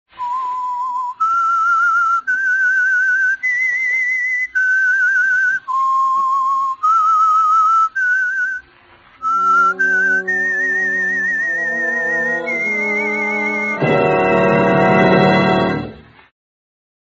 Und dann pfeift er